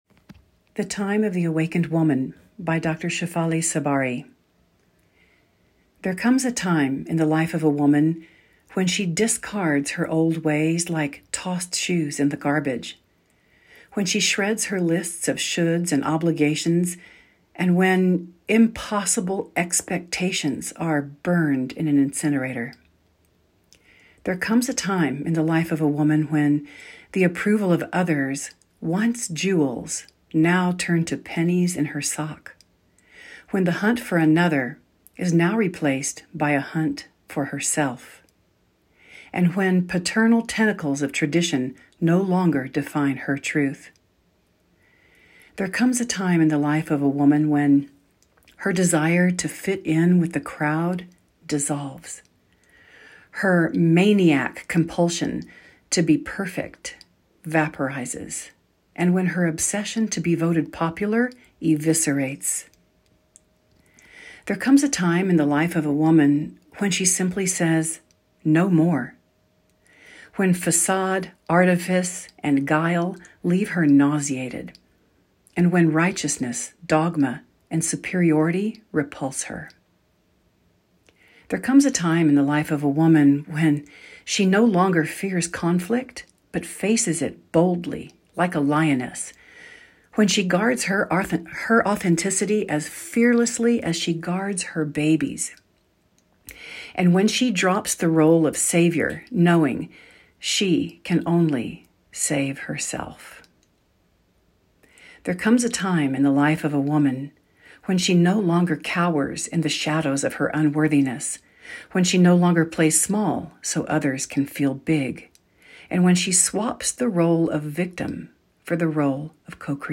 Click here to listen to today's poem.
Therefore, I've recorded myself reading the poem to you out loud.